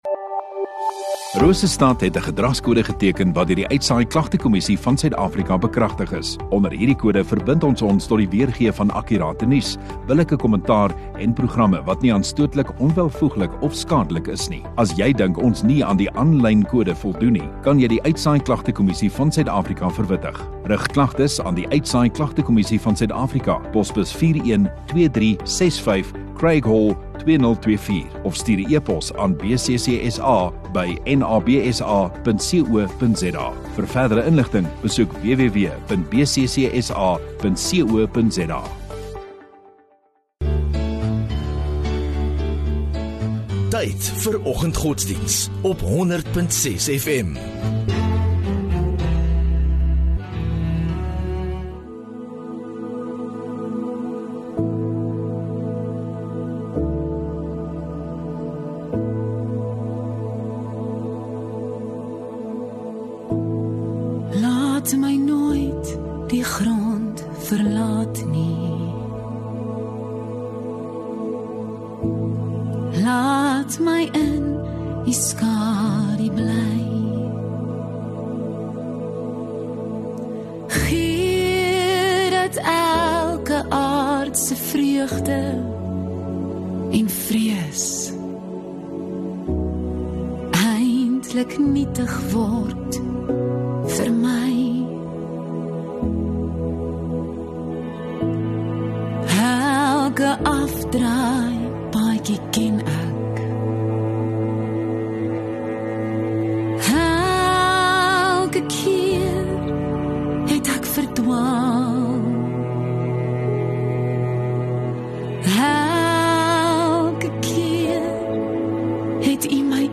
12 Feb Woensdag Oggenddiens